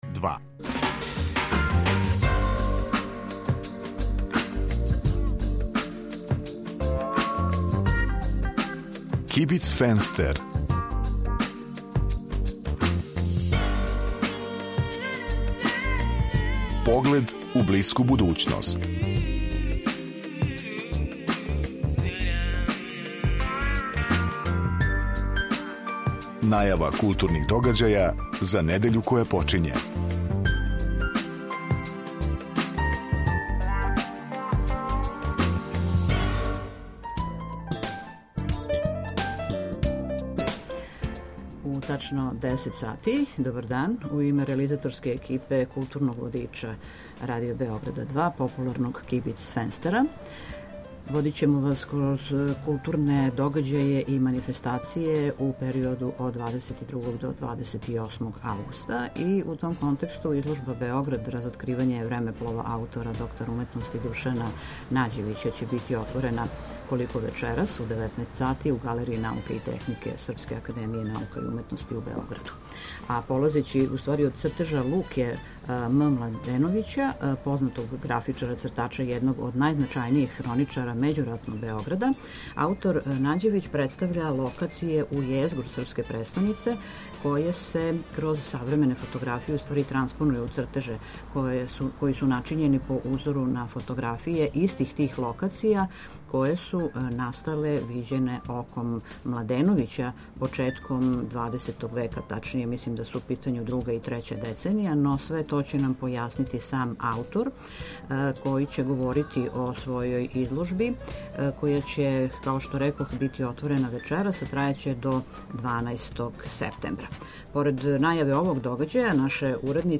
Недељни културно-уметнички водич